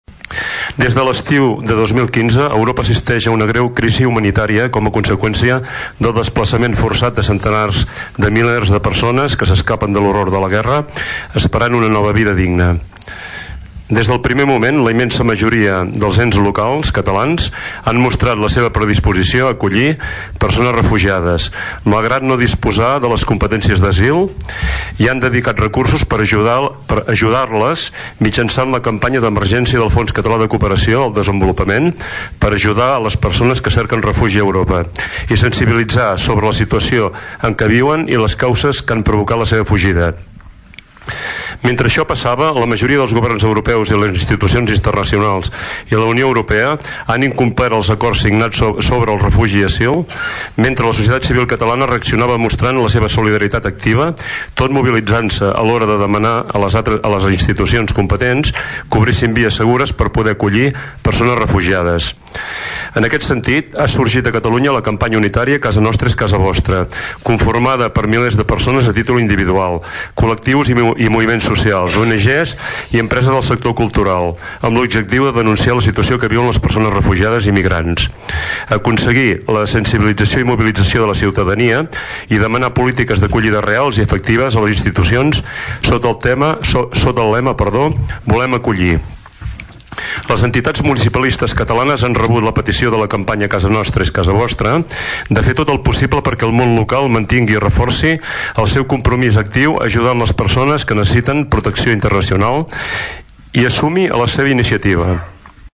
Durant el plenari es va llegir el següent manifest, consensuat per totes les forces polítiques. Ho llegia el regidor de serveis personals, Àngel Pous.